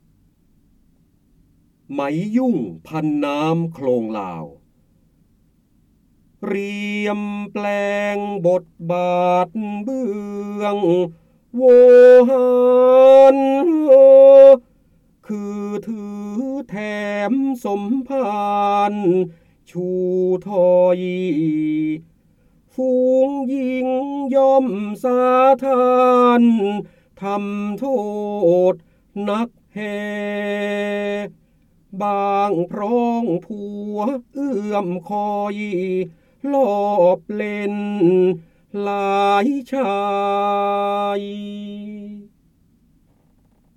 เสียงบรรยายจากหนังสือ จินดามณี (พระโหราธิบดี) ไหมยุ่งพันน้ำโคลงลาว
ลักษณะของสื่อ :   คลิปเสียง, คลิปการเรียนรู้